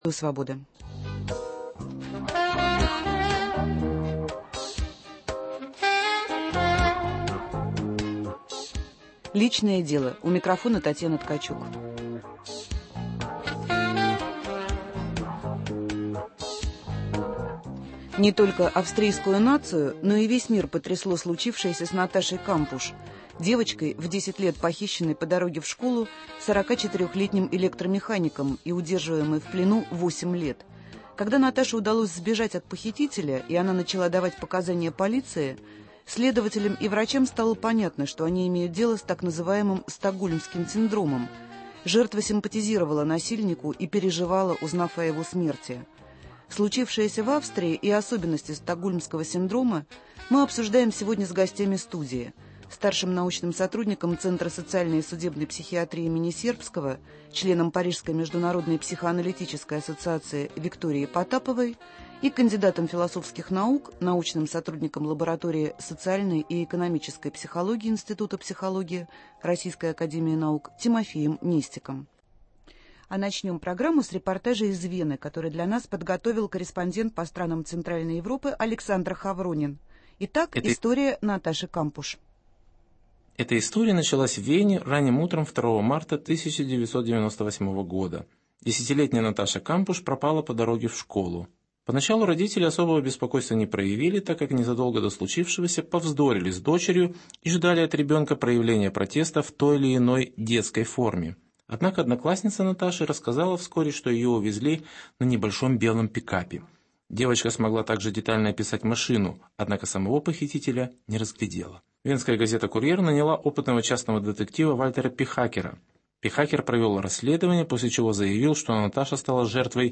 Мы будем говорить о случившемся с австрийской девушкой Наташей Кампуш. В студию приглашены психолог и психоаналитик.